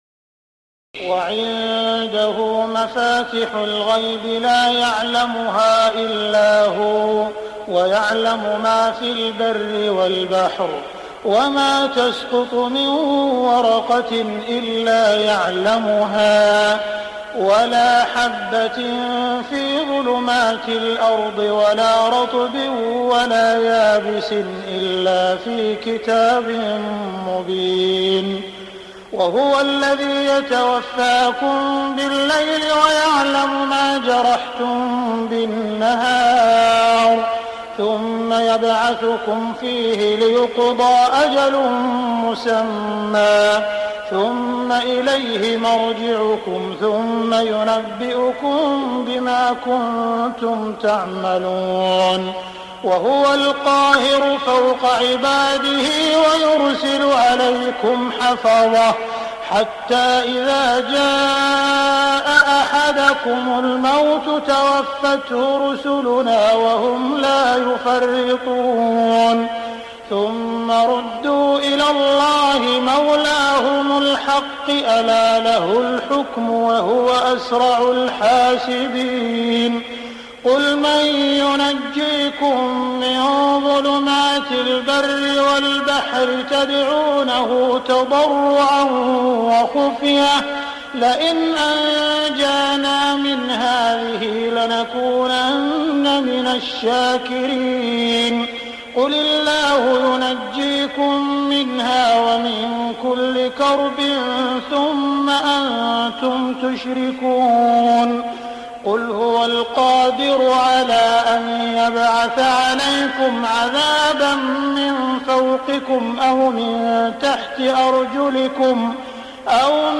تهجد ليلة 27 رمضان 1418هـ من سورة الأنعام (59-111) Tahajjud 27 st night Ramadan 1418H from Surah Al-An’aam > تراويح الحرم المكي عام 1418 🕋 > التراويح - تلاوات الحرمين